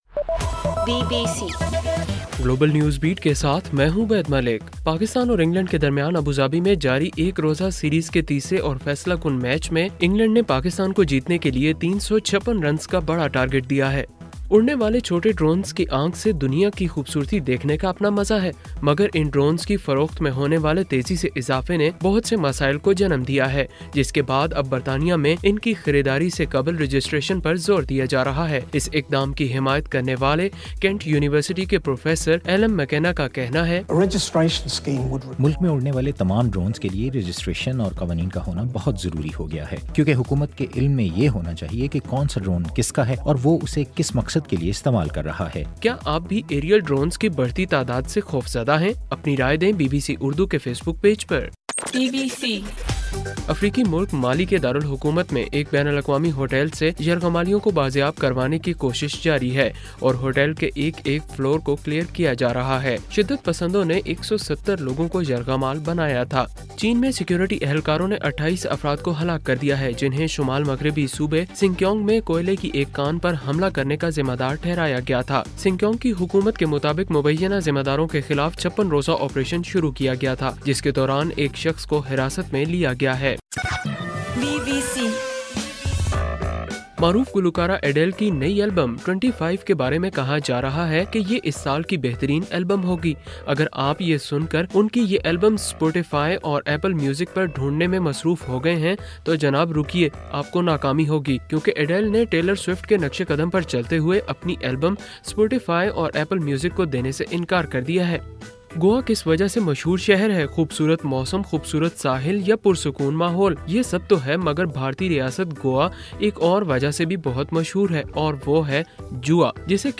نومبر 20: رات 9 بجے کا گلوبل نیوز بیٹ بُلیٹن